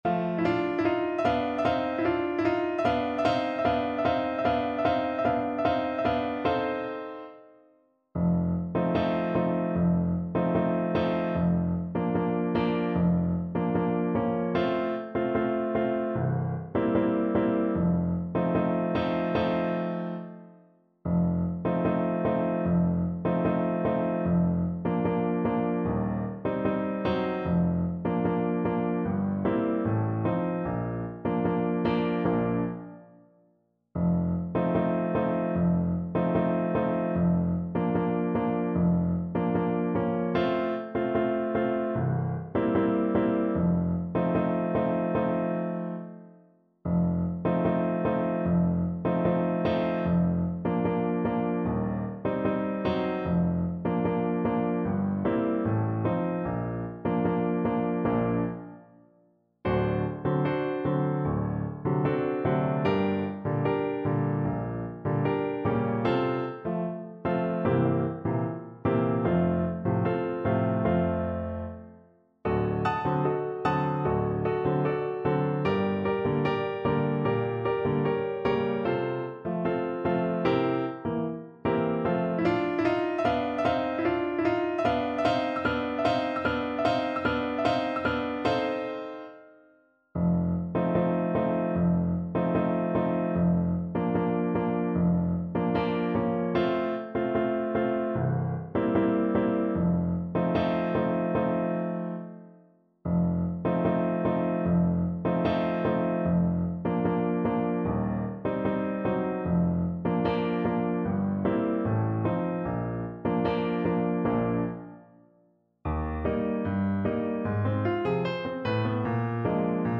Play (or use space bar on your keyboard) Pause Music Playalong - Piano Accompaniment Playalong Band Accompaniment not yet available transpose reset tempo print settings full screen
Clarinet
Bb major (Sounding Pitch) C major (Clarinet in Bb) (View more Bb major Music for Clarinet )
2/4 (View more 2/4 Music)
World (View more World Clarinet Music)
Brazilian Choro for Clarinet